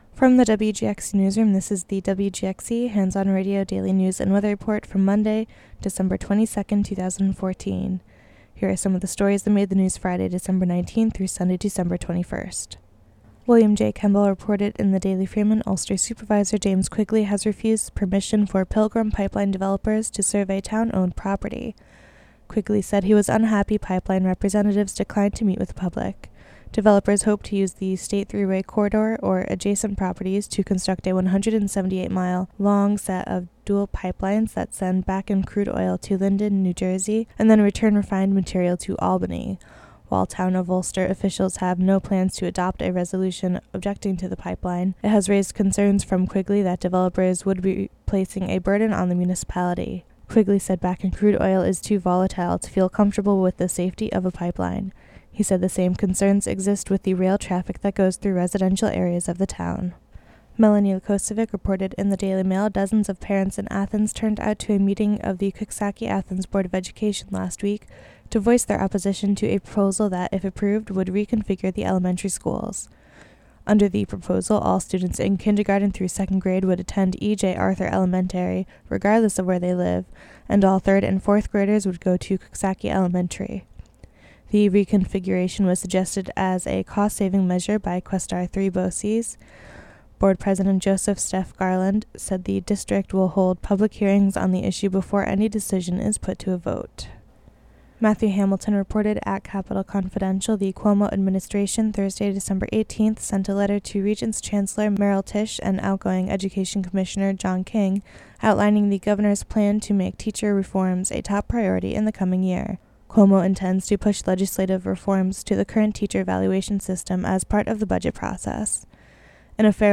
Local news and weather for Monday, December 22, 2014.